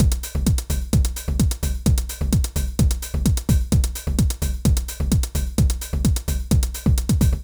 INT Beat - Mix 7.wav